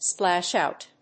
splash+out.mp3